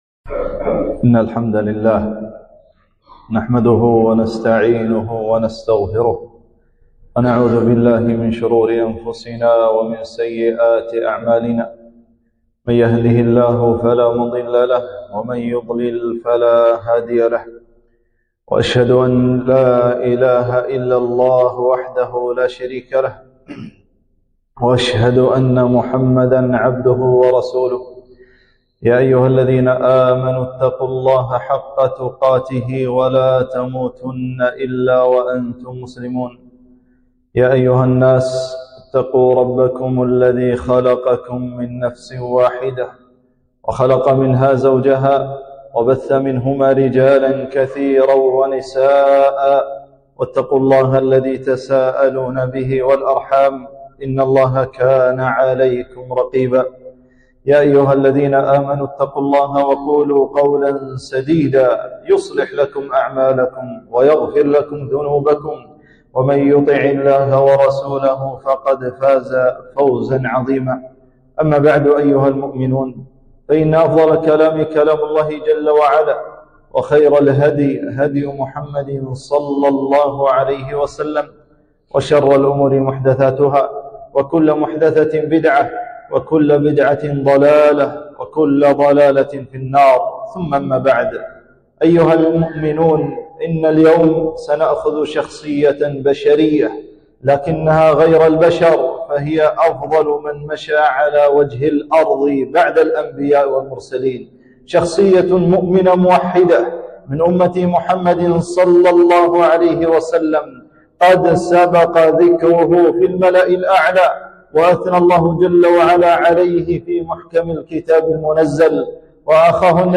خطبة - فضائل أبي بكر الصديق رضي الله عنه